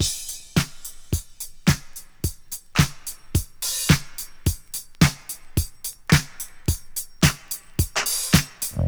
• 108 Bpm Drum Groove F# Key.wav
Free drum groove - kick tuned to the F# note. Loudest frequency: 3070Hz
108-bpm-drum-groove-f-sharp-key-53y.wav